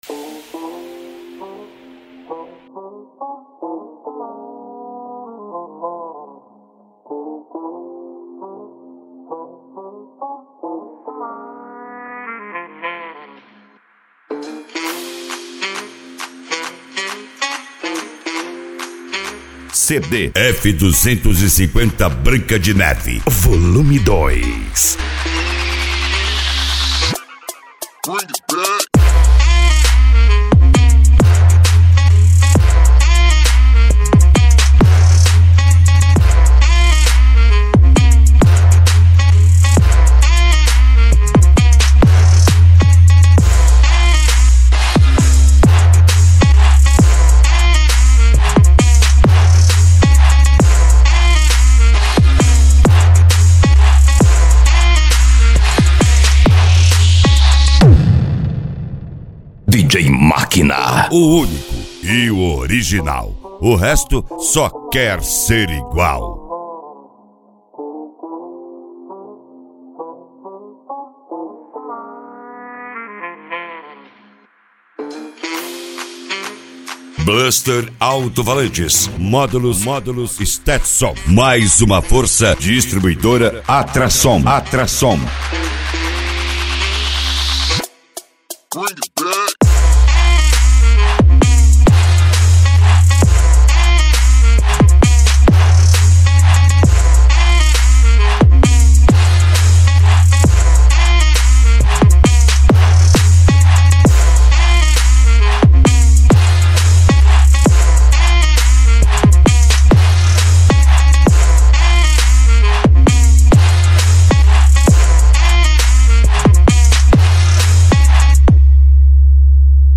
Funk Nejo
Hard Style
PANCADÃO